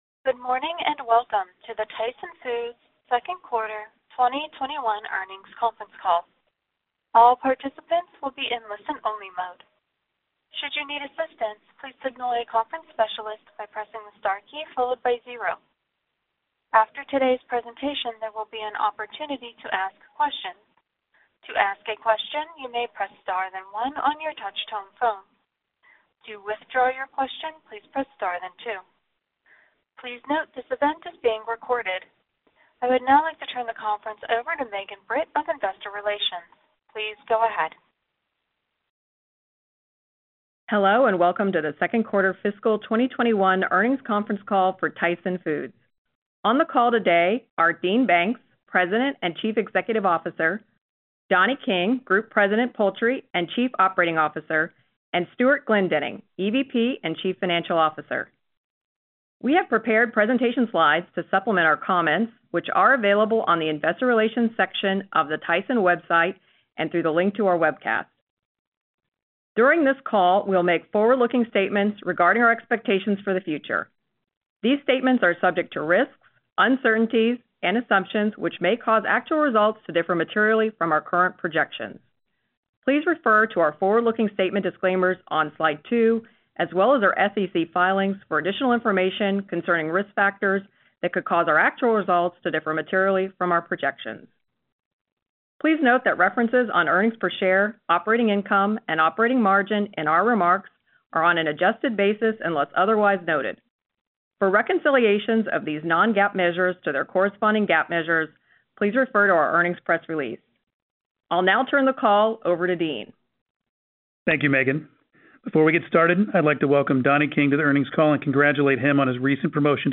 Tyson Foods Inc. - Q2 2021 Tyson Foods Earnings Conference Call